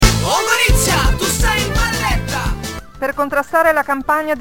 Lo spot alla radio